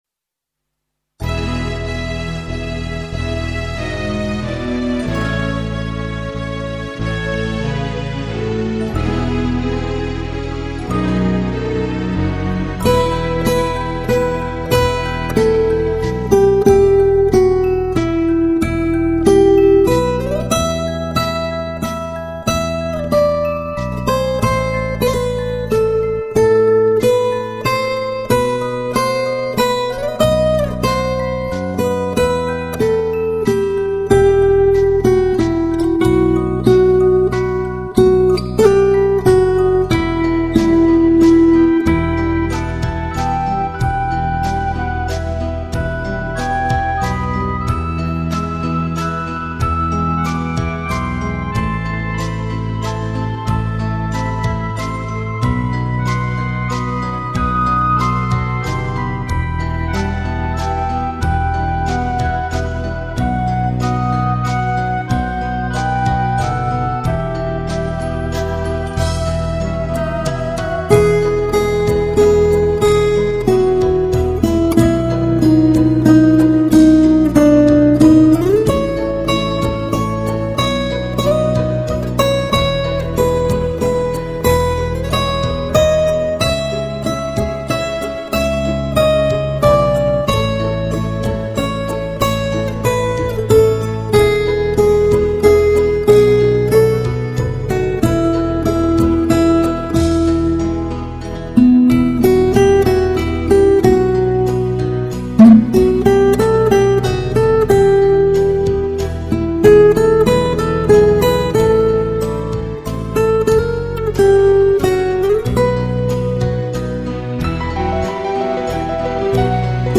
0125-吉他名曲爱情浪漫曲.mp3